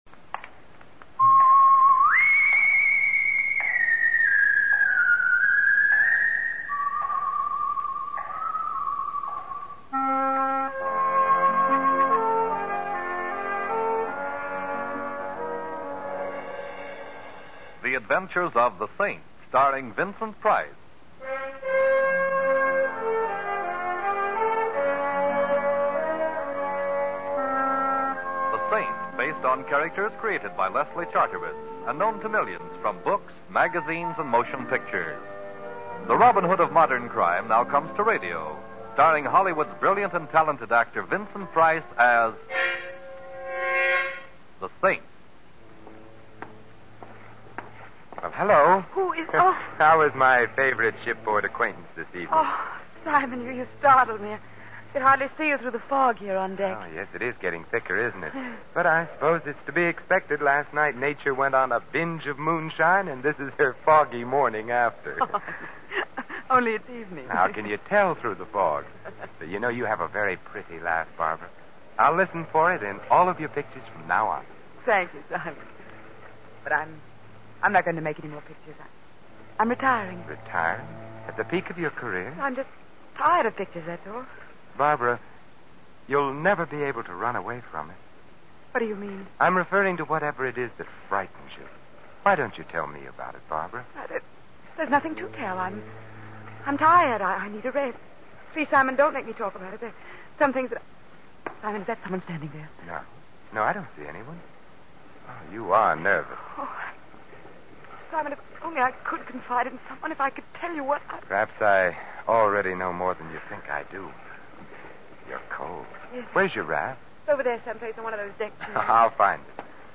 The Saint Radio Program starring Vincent Price